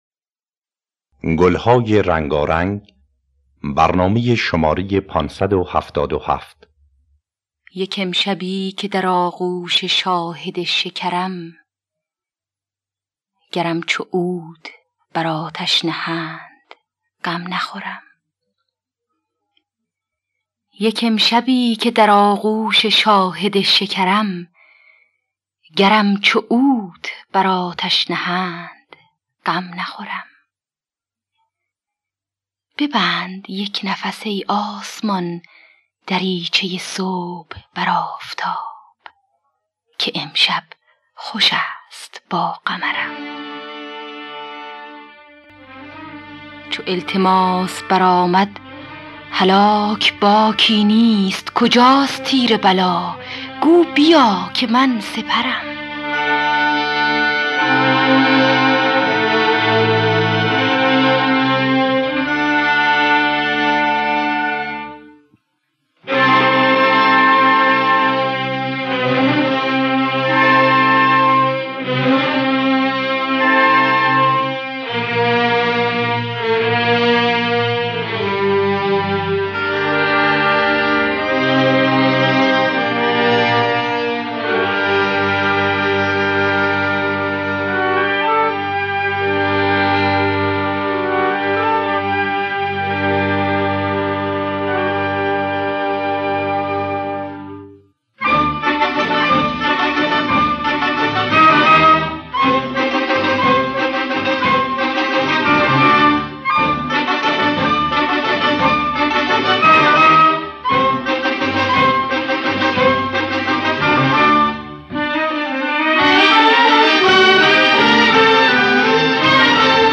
دانلود گلهای رنگارنگ ۵۷۷ با صدای سیما بینا، عبدالوهاب شهیدی در دستگاه بیات اصفهان. آرشیو کامل برنامه‌های رادیو ایران با کیفیت بالا.